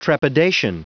Prononciation du mot : trepidation
trepidation.wav